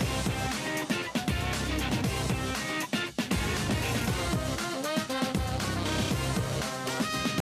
saw.wav